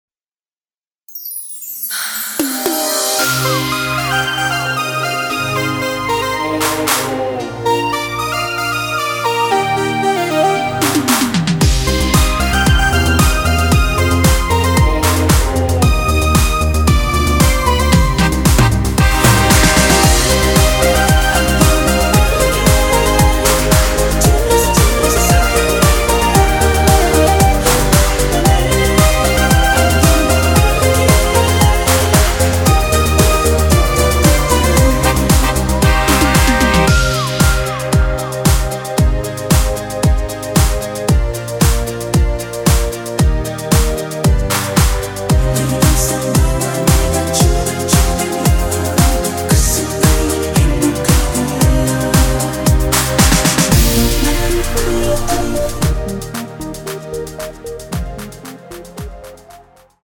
원키에서(-2)내린 코러스 포함된 MR입니다.(미리듣기 확인)
Cm
앞부분30초, 뒷부분30초씩 편집해서 올려 드리고 있습니다.
중간에 음이 끈어지고 다시 나오는 이유는